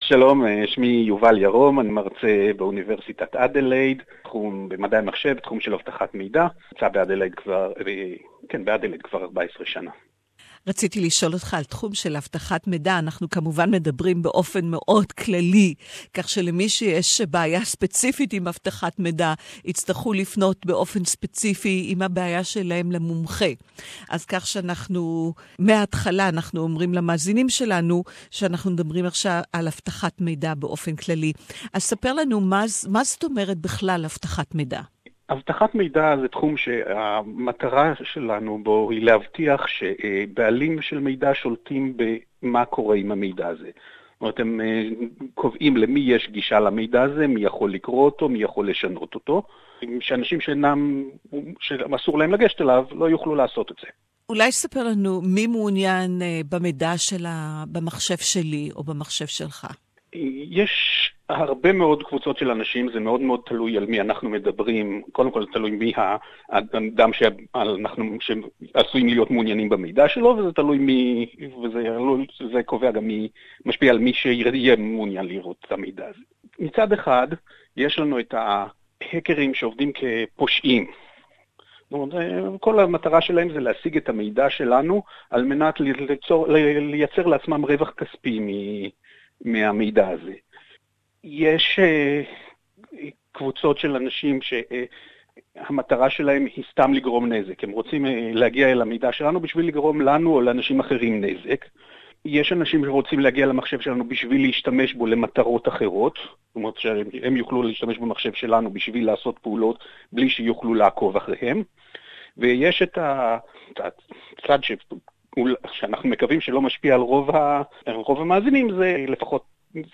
What do you know about computer security?find out more from the expert...interview in Hebrew